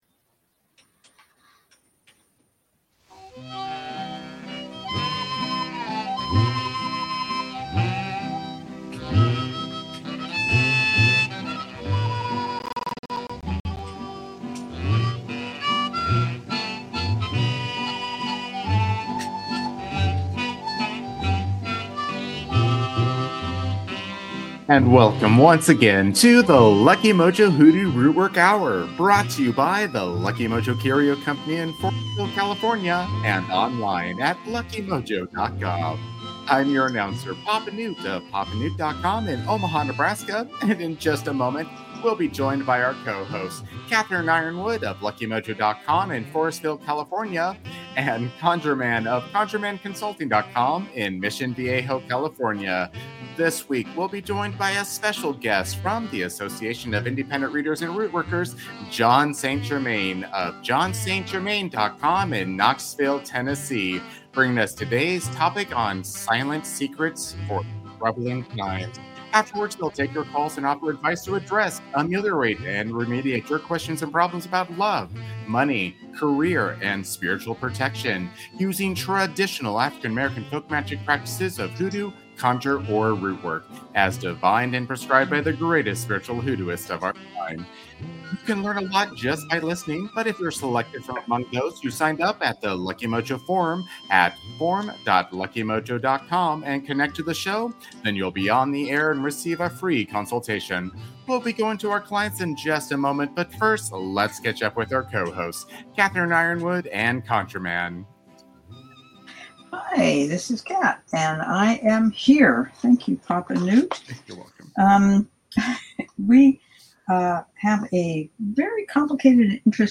It's the longest-running hoodoo conjure rootwork radio show in the UNIVERSE!